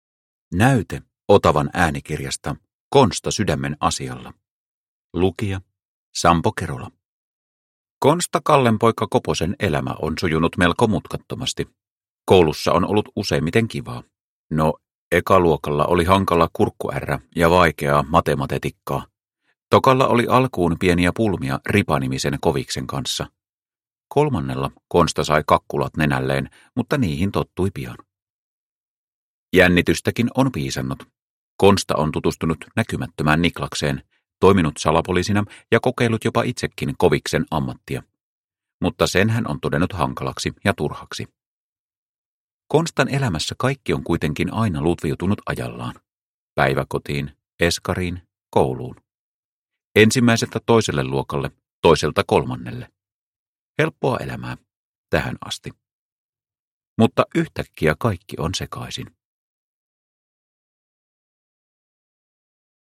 Konsta sydämen asialla – Ljudbok – Laddas ner